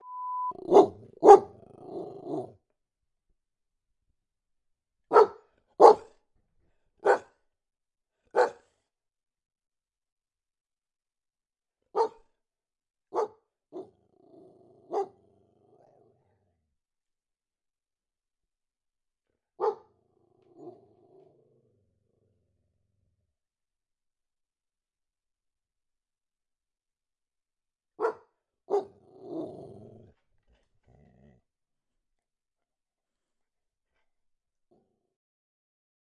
家养动物 " 单一小型犬愤怒的叫声
模拟立体声，数字增强。
标签： 愤怒 树皮 p uppy 小型狗 狂吠
声道立体声